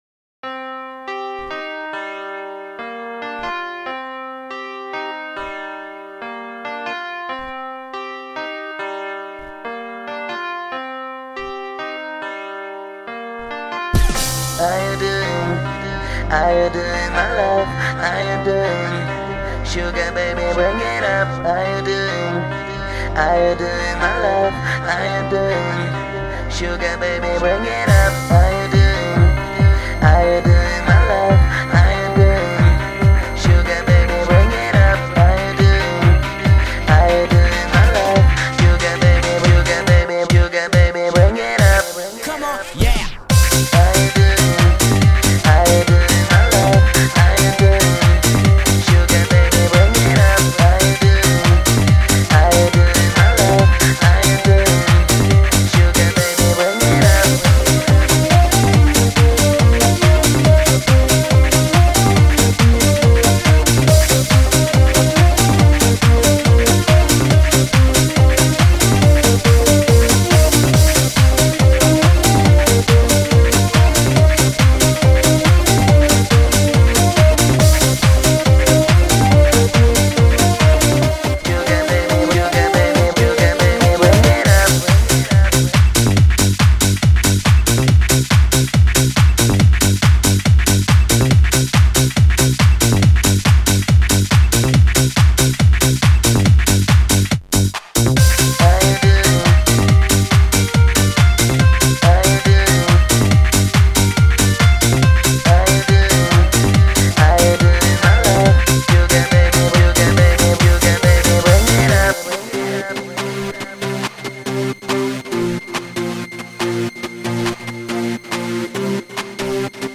Жанр: Жанры / Поп-музыка